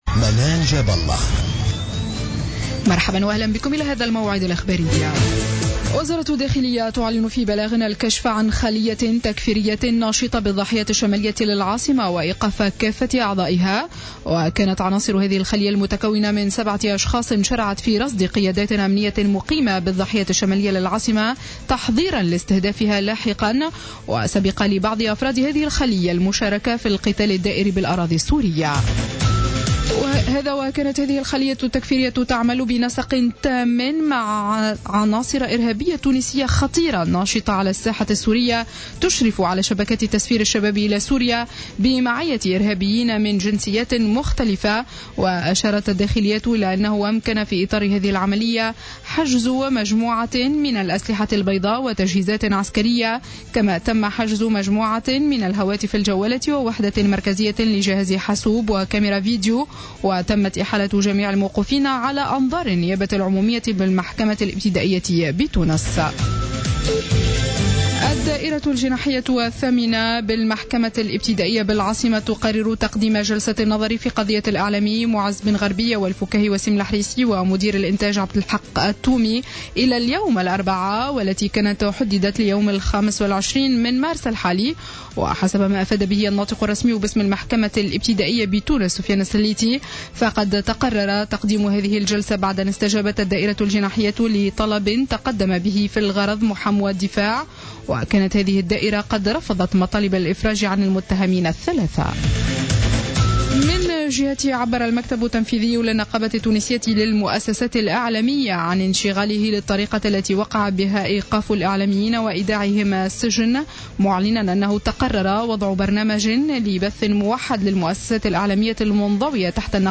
نشرة أخبار منتصف الليل ليوم الاربعاء 18 مارس 2015